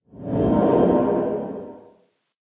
cave12.ogg